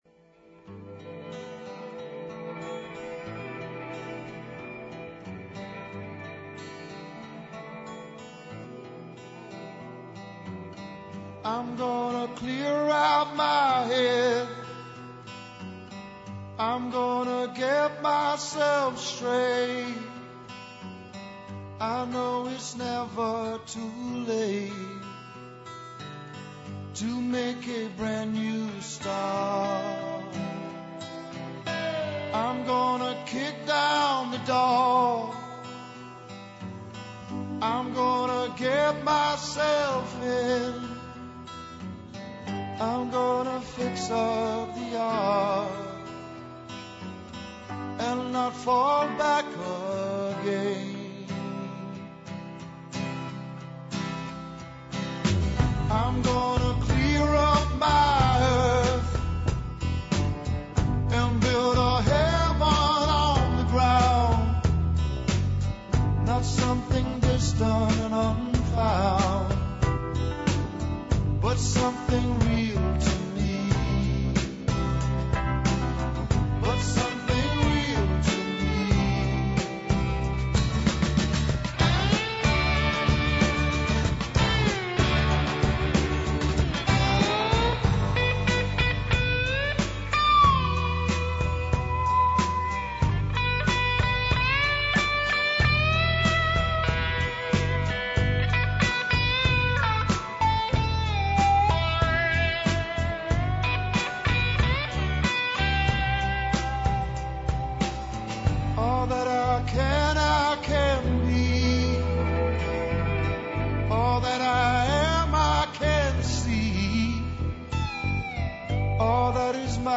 Ту је и одлична музика која ће вам олакшати сваки минут, било где да се налазите.